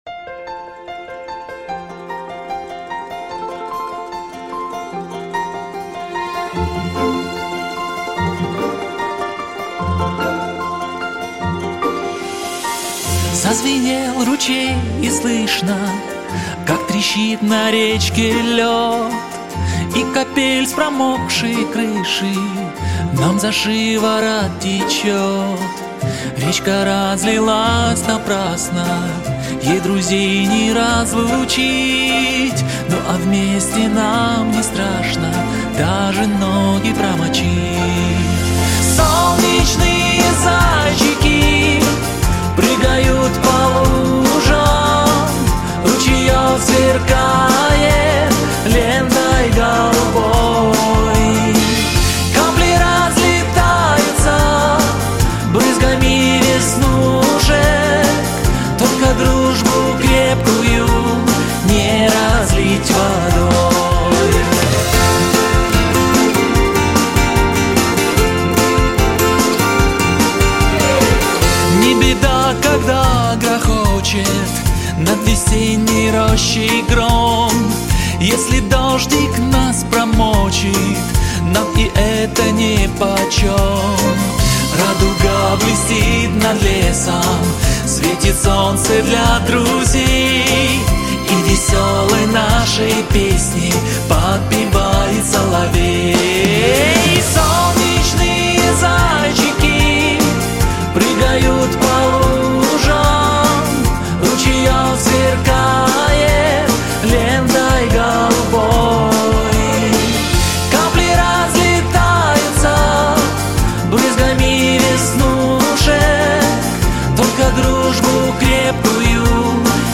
Жанр: "Acapella"